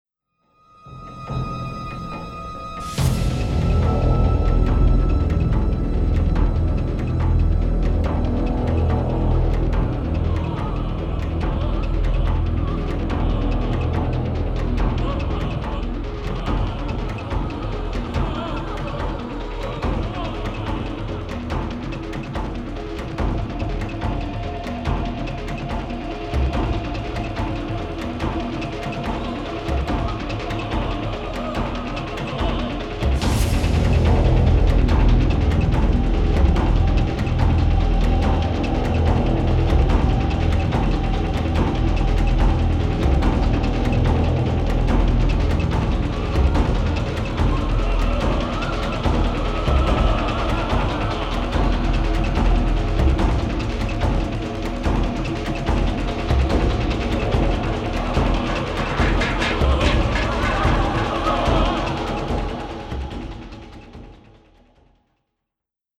hip-hop
to English electronica
synthesizers, orchestra and choir
an unusually subtle yet powerful and dramatic score.